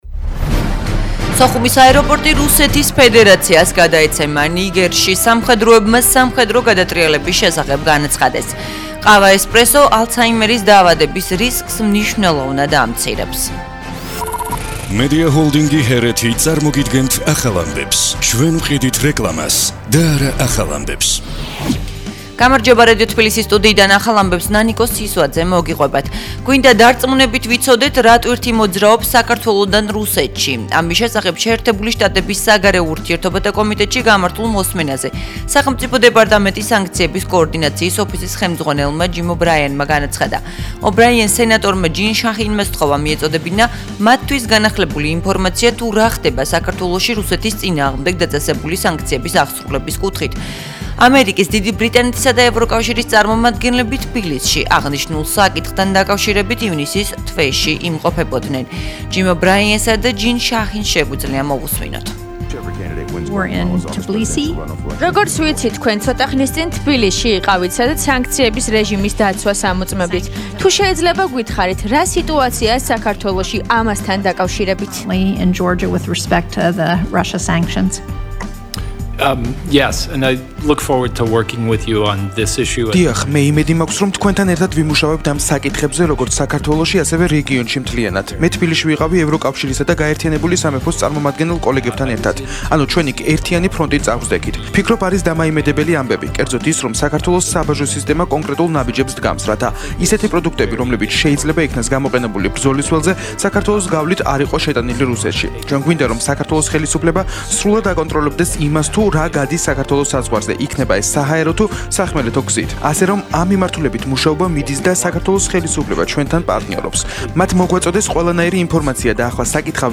ახალი ამბები 14:00 საათზე
ახალი ამბები